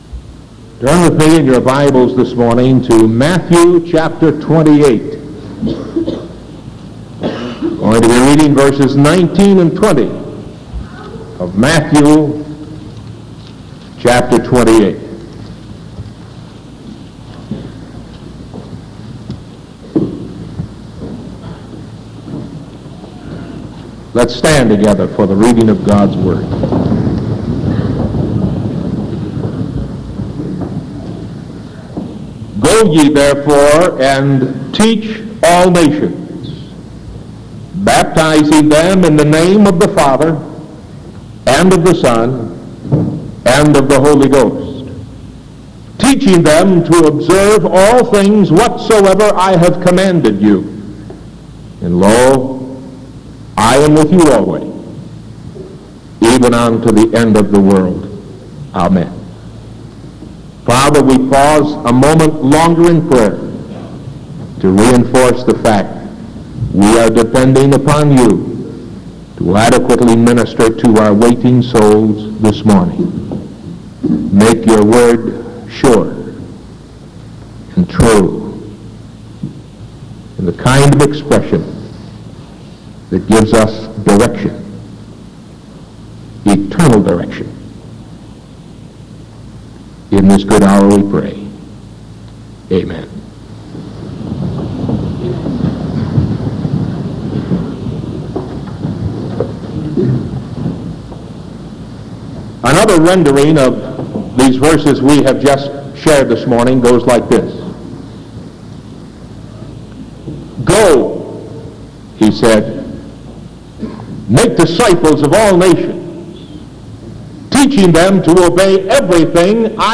Sermon September 16th 1973 AM